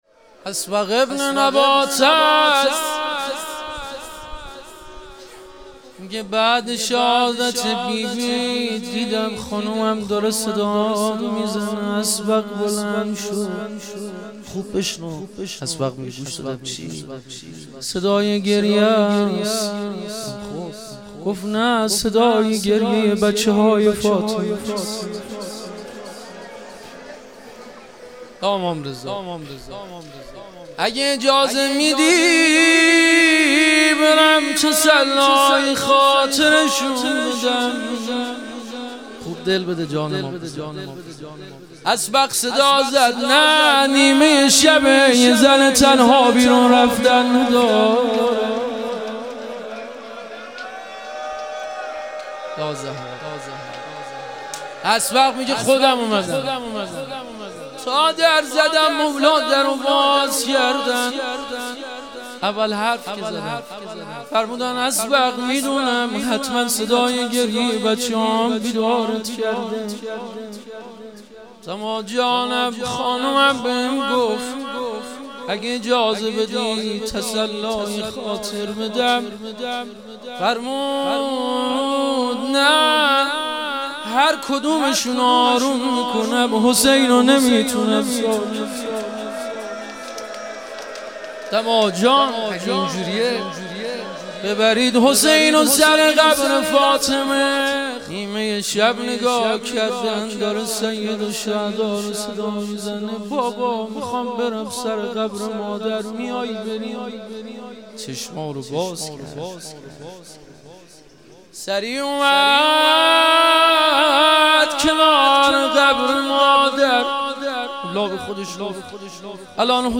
ایام فاطمیه دوم - روضه